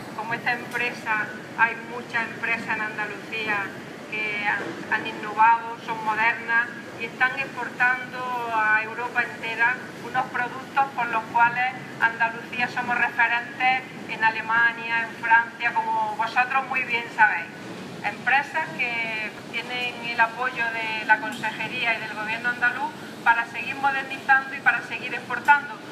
Declaraciones de la consejera sobre Royal SAT